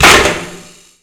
bolt_skewer2.wav